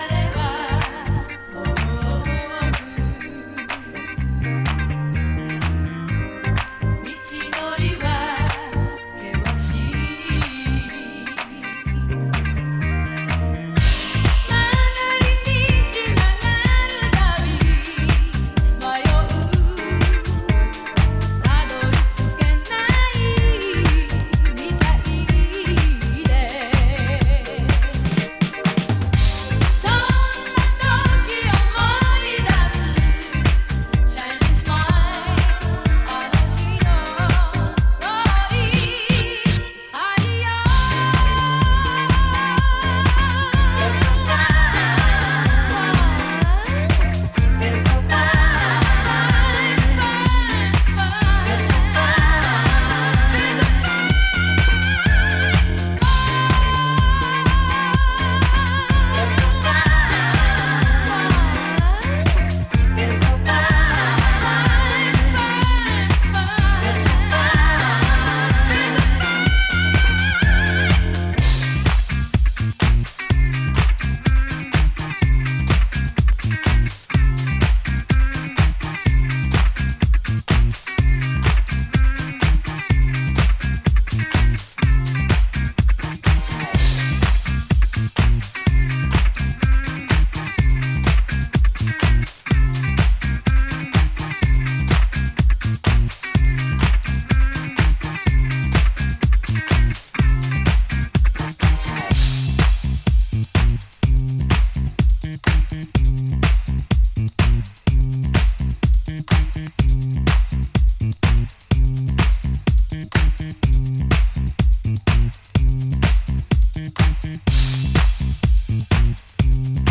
例に漏れず、全曲DJユースで強力なリエディット集となっています。